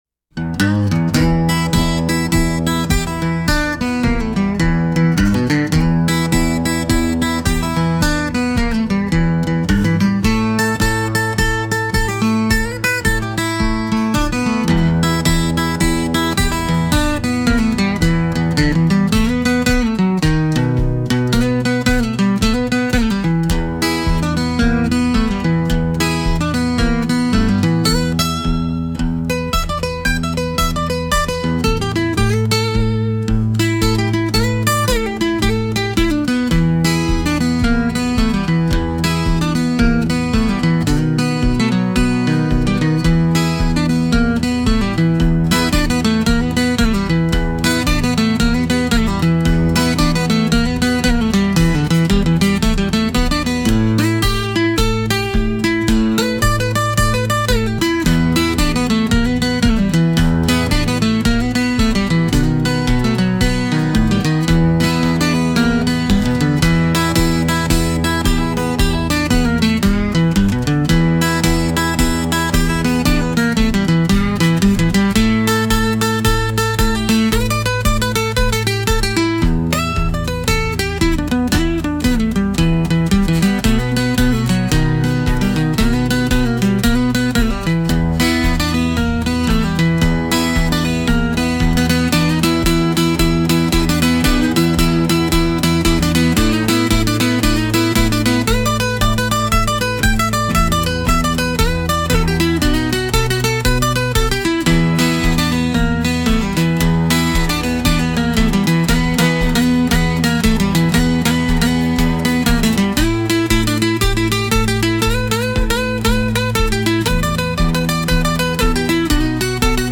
Instrumental - Ink & Ether - 4 mins